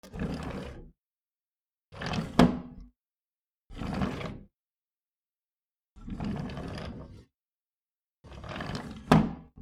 木の棚 『ガラ』 up